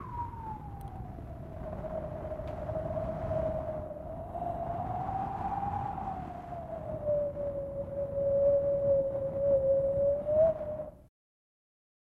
风 " 北极风暴微风018
描述：冬天来了，所以我创造了一些冷冬季的声音。这里变冷了！
Tag: 风暴 微风 大风 北极